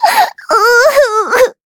Taily-Vox_Sad.wav